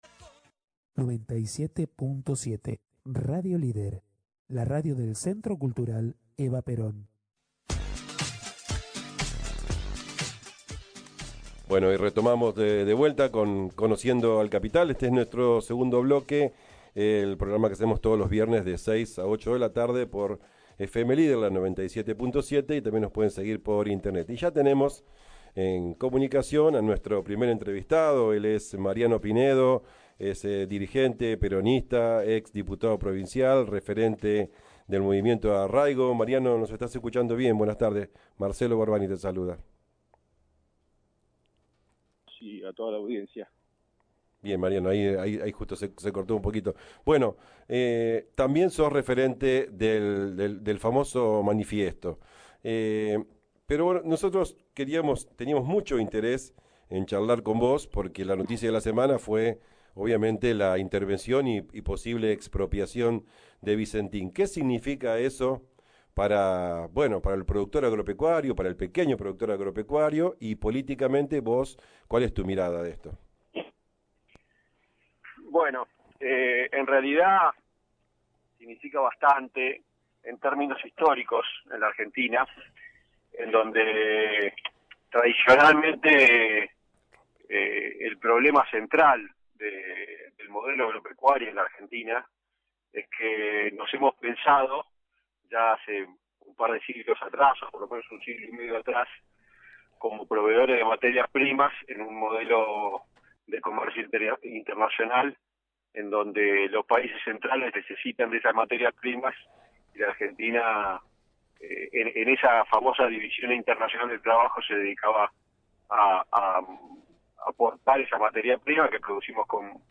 Conociendo al Capital conversó el dirigente peronista Mariano Pinedo, referente del Movimiento Arraigo, ex diputado provincial y un conocedor del sector de los pequeños productores agropecuarios, sector para el cual presentó diversos proyectos en su defensa y desarrollo mientras ocupó una banca de diputado de la provincia de Buenos Aires entre 2015 y 2019.
Vicentín fue el centro de la entrevista donde Mariano Pinedo dejó claro el papel fundamental que juega esta empresa en el negocio agro-financiero. Explicó, el negocio de la empresa se sustentaba en tres patas, la especulación financiera, mediante fuga de capitales y endeudamiento, el manejo y control de los puertos y vías navegables internas y la permamente evasión impositiva, con protección del estado neoliberal macrista, para producir una quiebra fraudulenta.